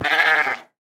Minecraft Version Minecraft Version snapshot Latest Release | Latest Snapshot snapshot / assets / minecraft / sounds / mob / goat / death5.ogg Compare With Compare With Latest Release | Latest Snapshot
death5.ogg